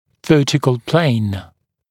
[‘vɜːtɪkl pleɪn][‘вё:тикл плэйн]вертикальная плоскость